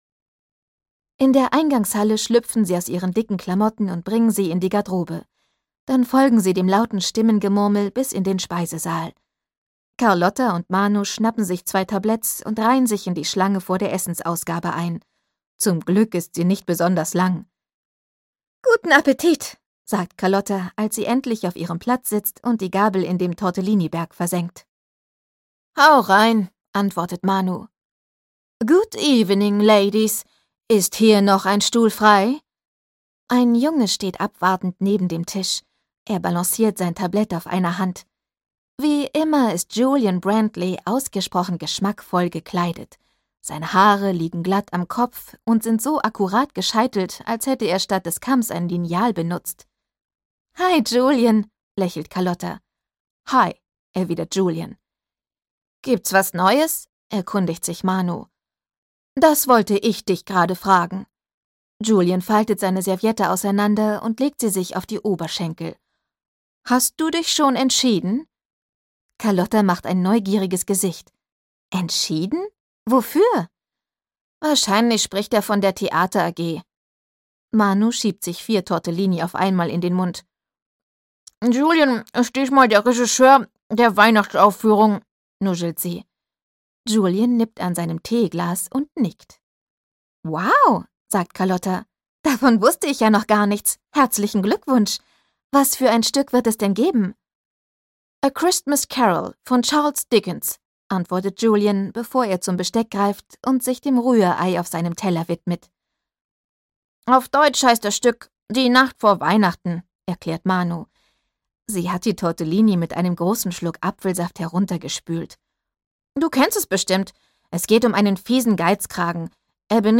Carlotta: Carlotta - Internat und Schneegestöber - Dagmar Hoßfeld - Hörbuch